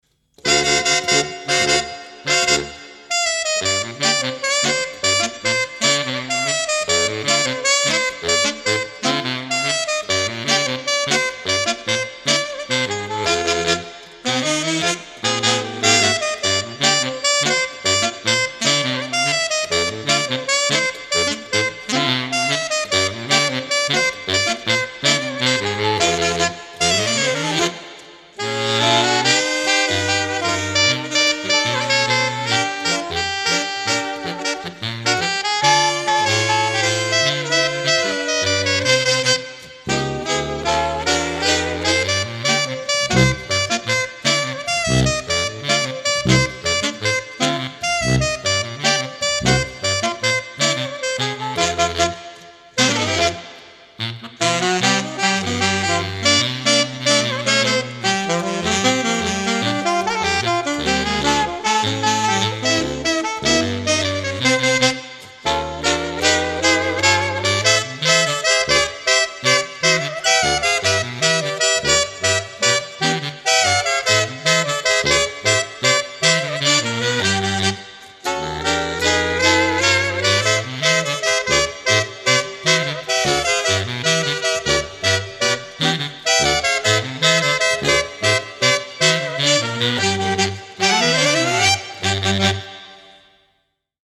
More Saxophone Quartet Music